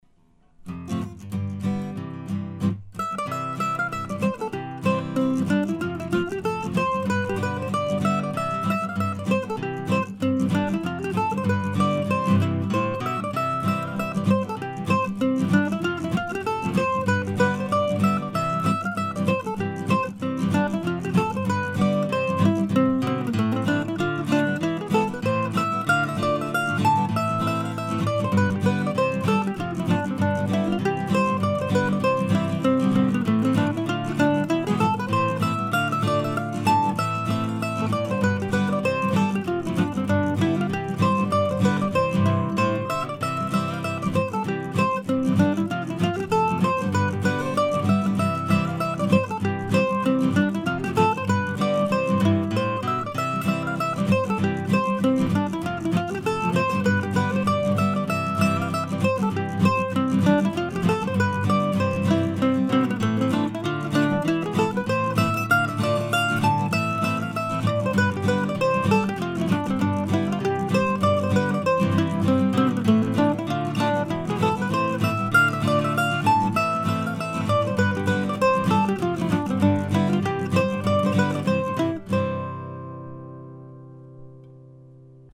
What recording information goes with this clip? As usual these were all recorded using my now trusty Zoom H4 recorder.